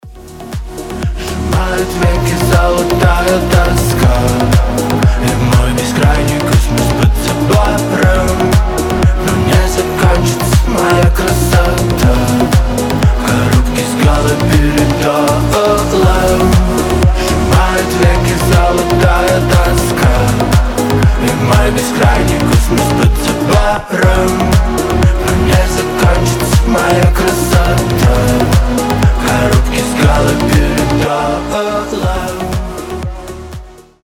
танцевальные